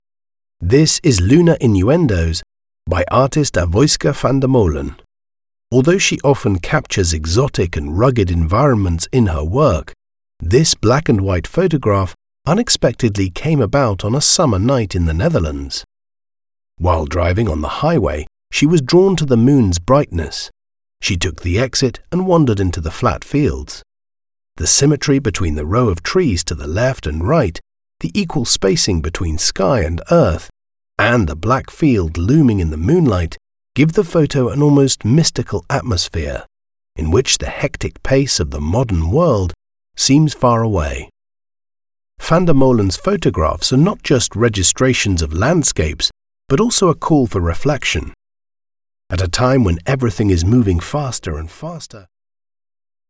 Engels (Brits)
Commercieel, Natuurlijk, Vertrouwd, Warm, Zakelijk
Audiogids